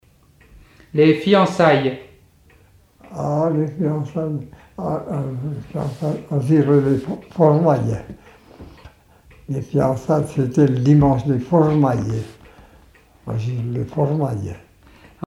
Enquête Haut-Jura
Catégorie Locution